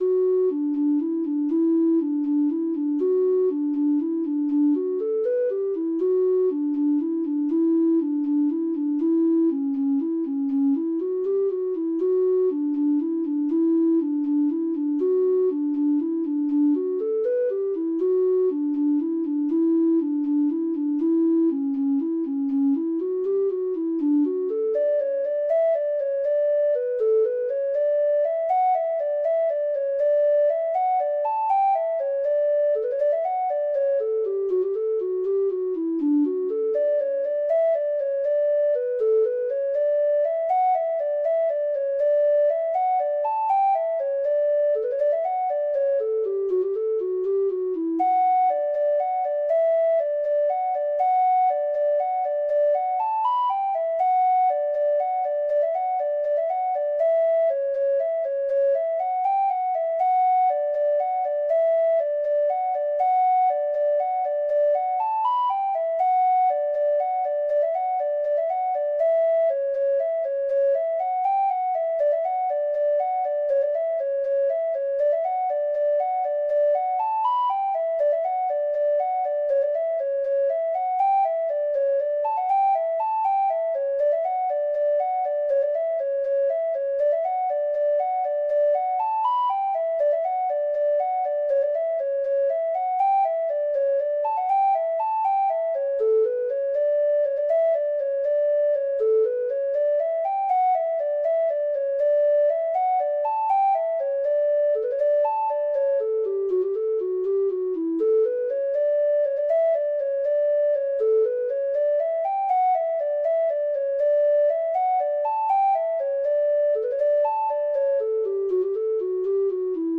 Traditional Trad. Wallop the Potlid (Irish Folk Song) (Ireland) Treble Clef Instrument version
Traditional Music of unknown author.
Irish